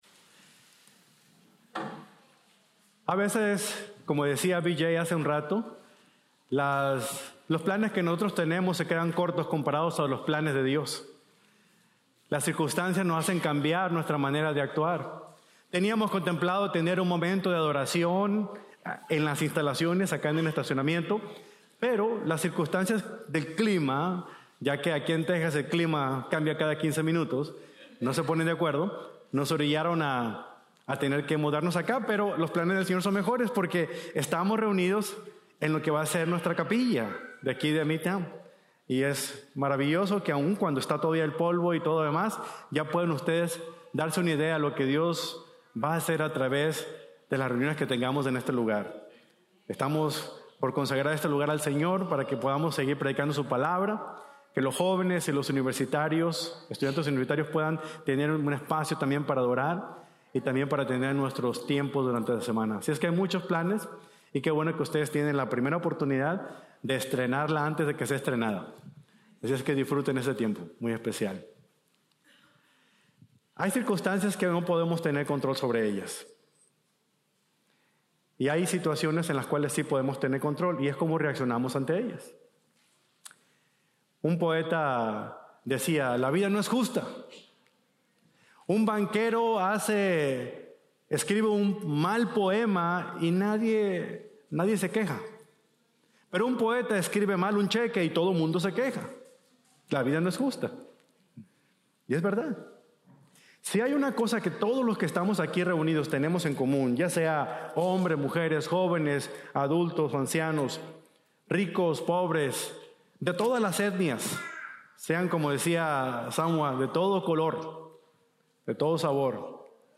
13 de Marzo | Sermon | Grace Bible Church